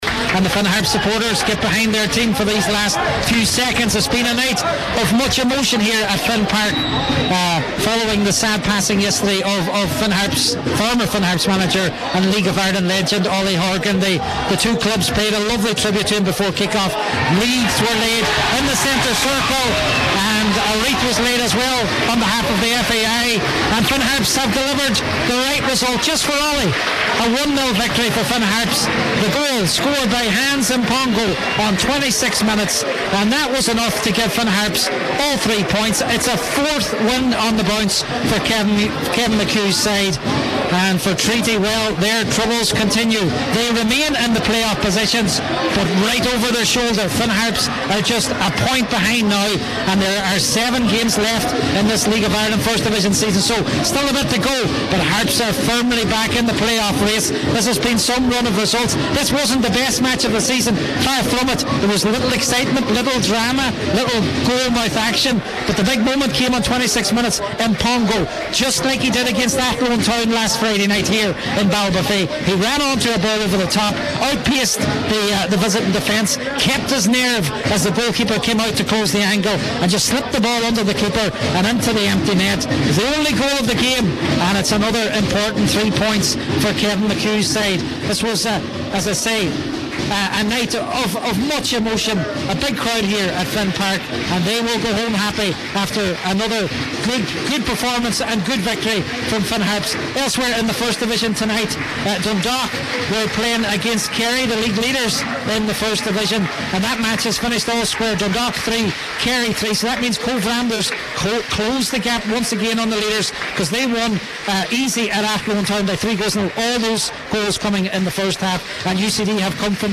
from Finn Park: